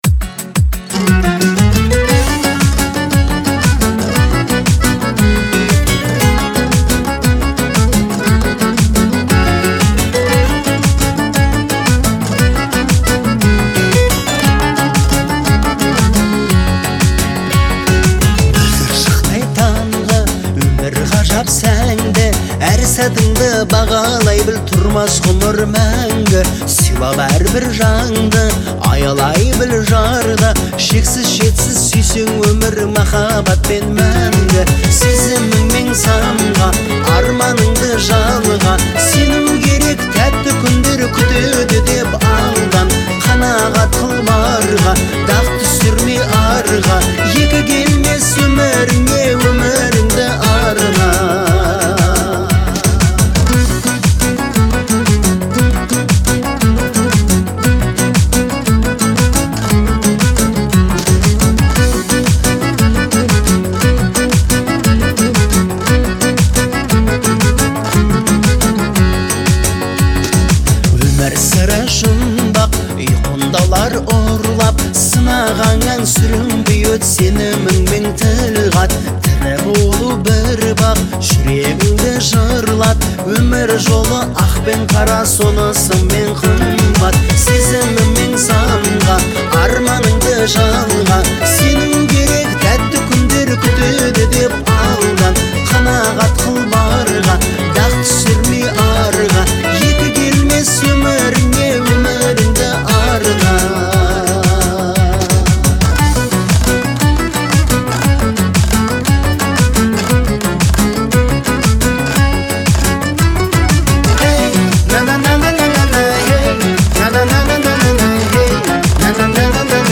Звучание трека отличается мелодичностью и гармонией